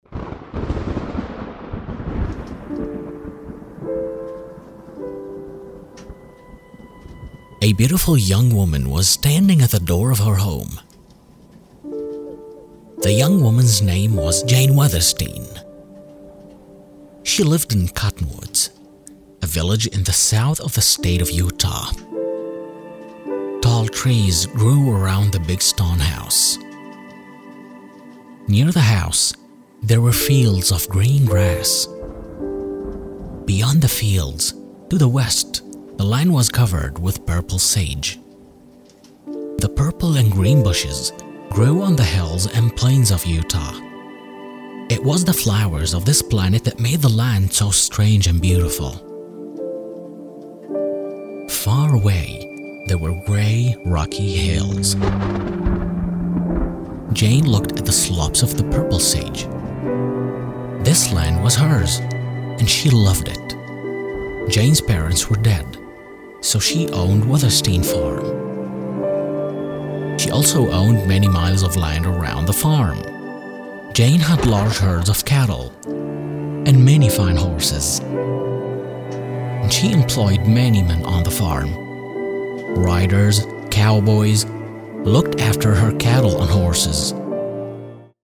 Male
English (North American)
Adult (30-50)
Believable , Bold , Calming , Caring , Commanding , Energized , Comic , Narrator
Male Voice Over Talent